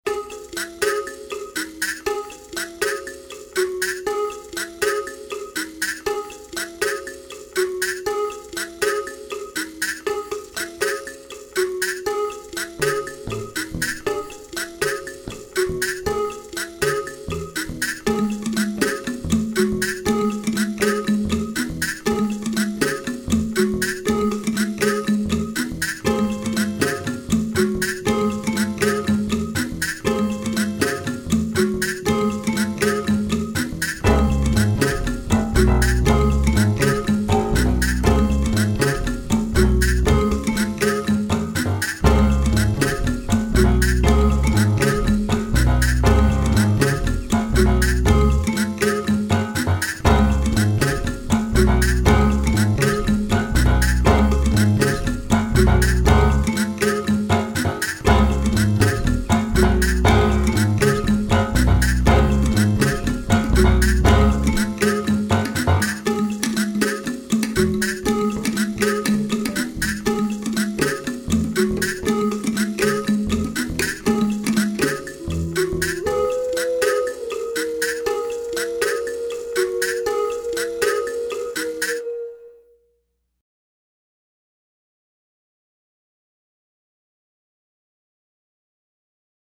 basson
hautbois
cor
trompette
trombone
violoncelle
batterie, percussions, choeurs
contrebasse
Evgueni Studio et Théâtre de Carouge